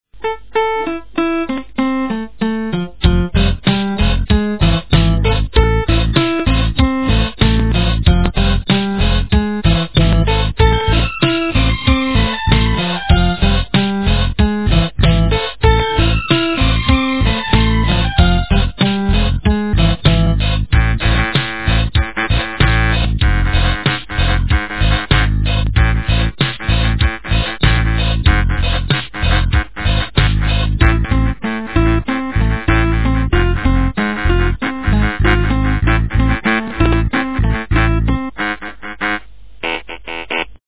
- фильмы, мультфильмы и телепередачи
качество понижено и присутствуют гудки.